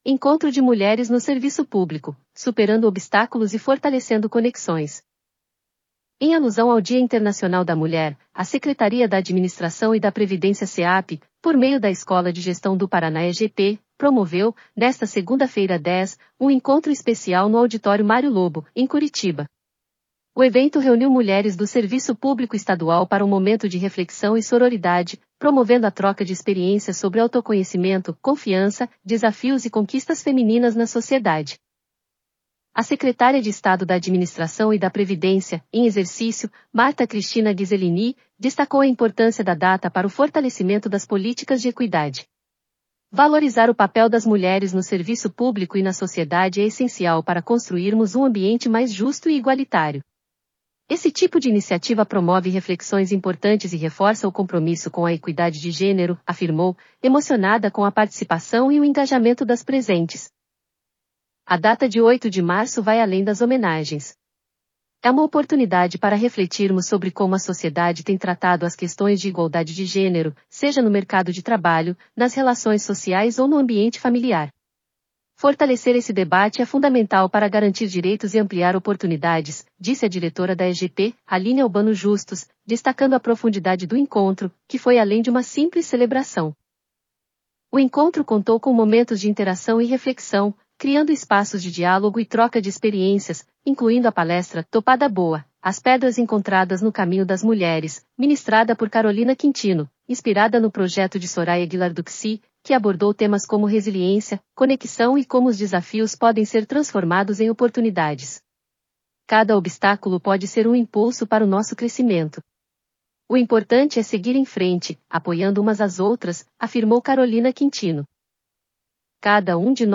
audionoticia_encontro_de_mulheres.mp3